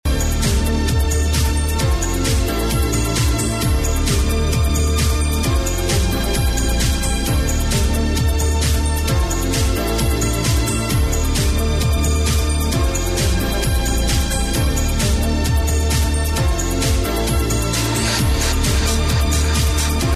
there are no vocals